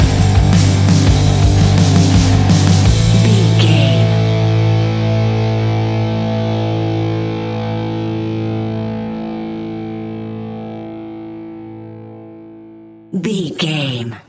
Epic / Action
Dorian
hard rock
blues rock
distortion
rock instrumentals
rock guitars
Rock Bass
Rock Drums
heavy drums
distorted guitars
hammond organ